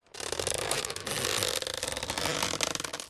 Звук скрипучей крышки гроба и шумное открывание